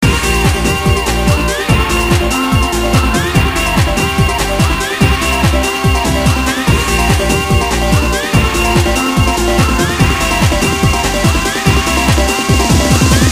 cheesy trance track